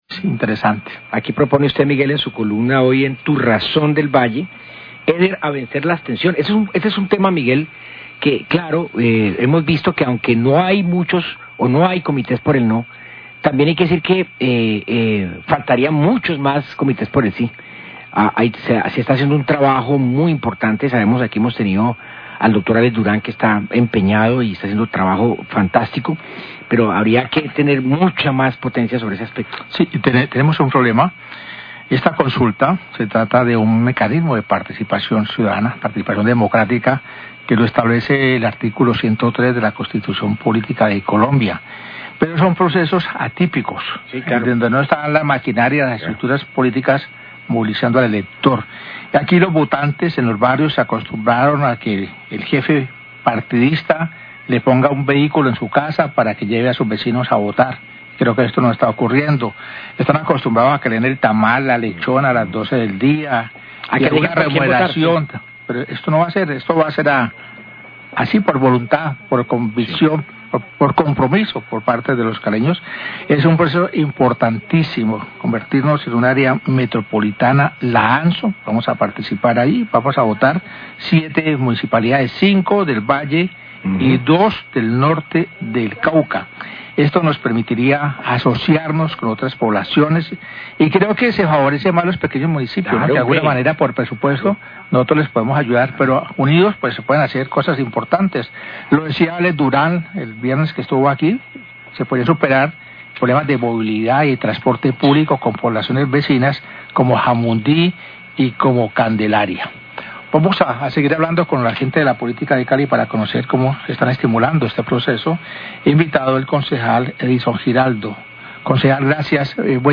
Radio
Concejal Edison Giraldo explicó lo que está haciendo para informar a los ciudadanos sobre la AMSO y porqué deberían votar sí el próximo domingo.